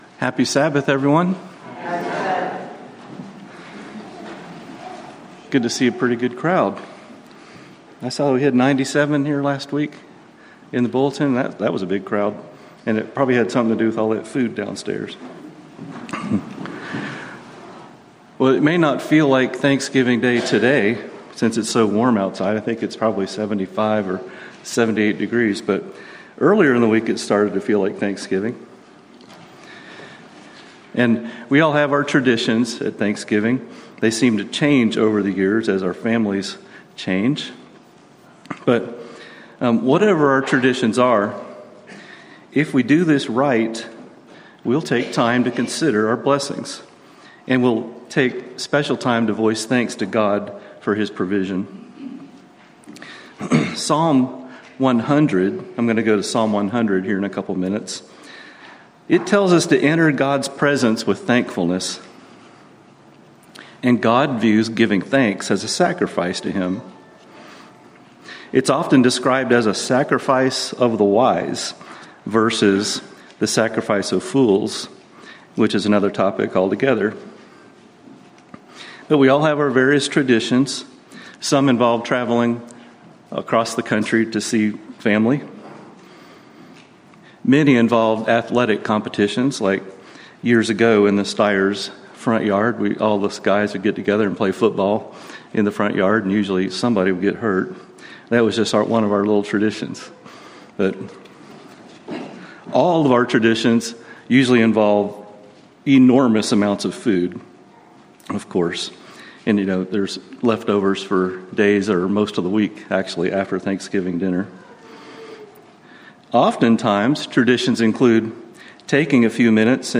Given in Nashville, TN